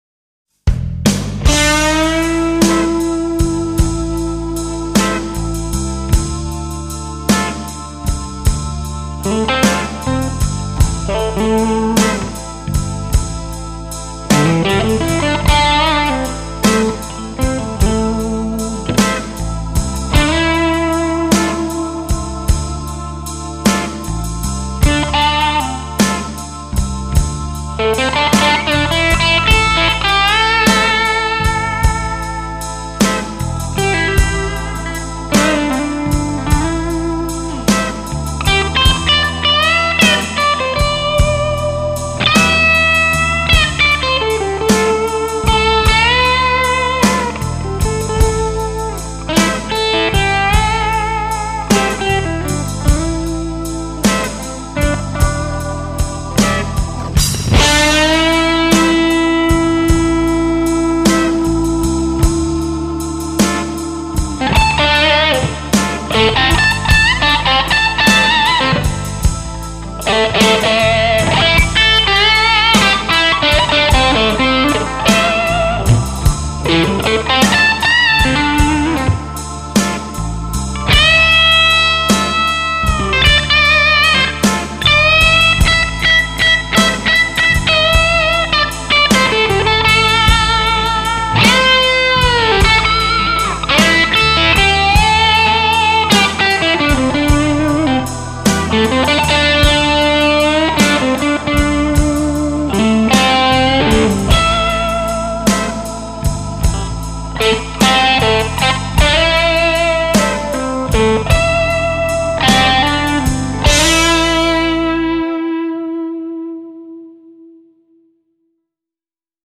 Da ich nicht ausschließen konnte, daß ich die Regler am Womanizer seit den letzten Clips nicht bewegt hatte, habe ich sowohl einen Take mit dem Originalpickup als auch einen mit dem neuen P90 eingespielt.
und zum Vergleich nochmal der alte Pickup mit einem neuen Soundfile:
P90-Test - Tokai Junior - stock-pickup - Damage Control Womanizer